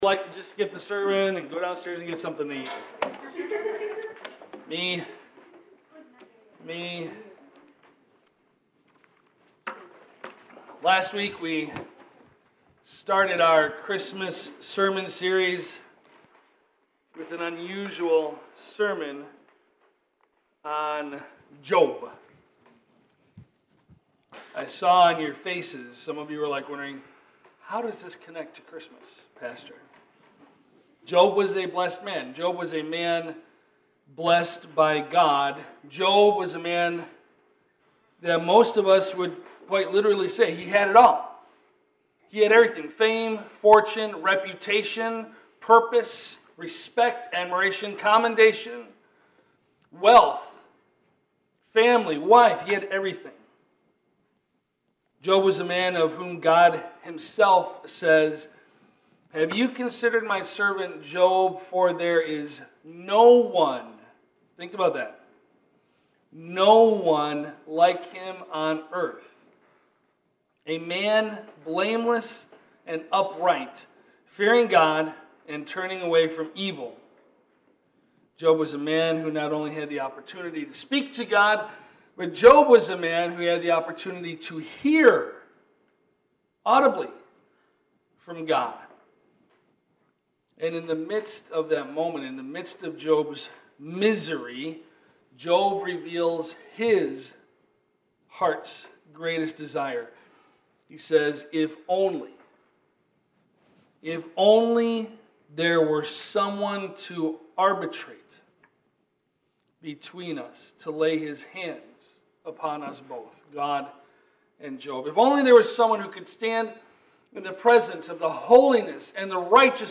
Zechariah’s Story Preacher